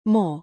・「R」の発音がアメリカ英語の方が強く発音する傾向があります。
more_e.mp3